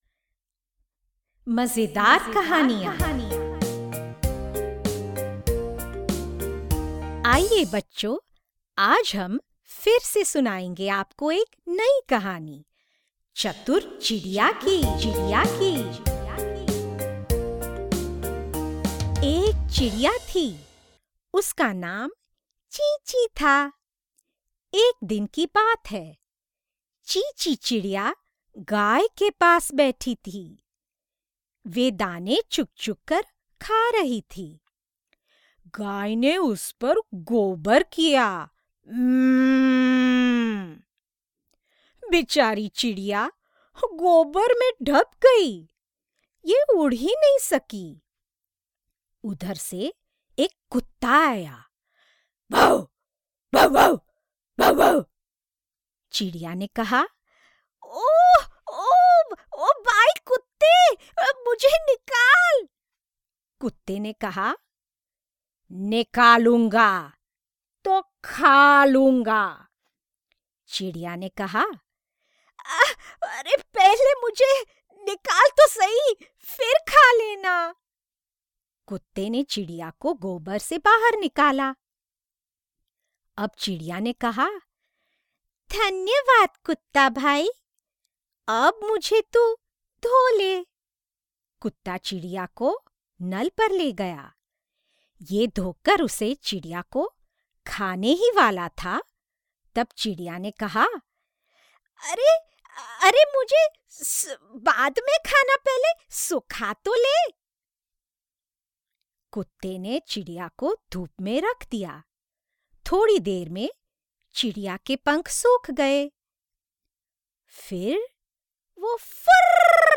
Clear diction and speech. Correct pronunciation, soft, warm, fun, sarcastic. Can modulate different characters.
Sprechprobe: Industrie (Muttersprache):